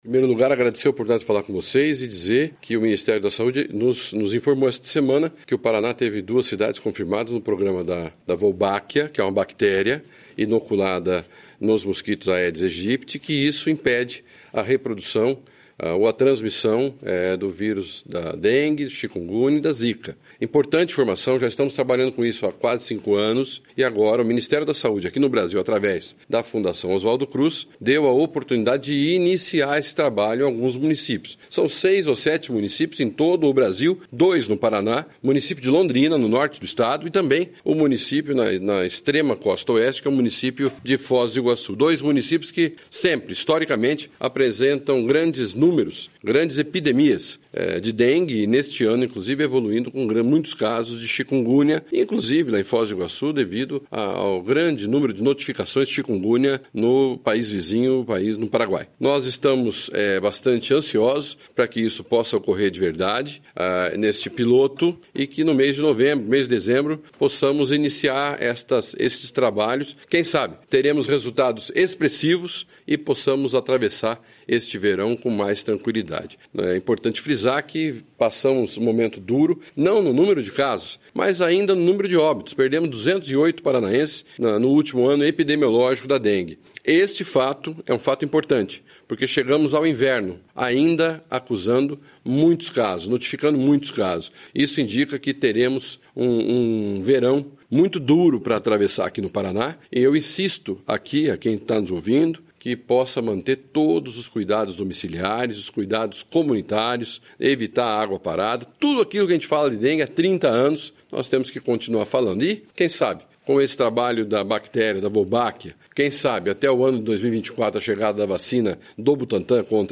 Sonora do secretário da Saúde, Beto Preto, sobre a participação de Foz do Iguaçu e Londrina no Método Wolbachia no Brasil, no combate à dengue